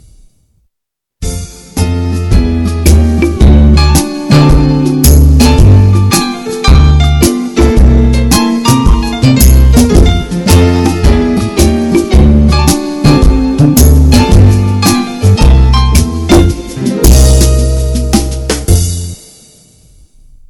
блюзик.mp3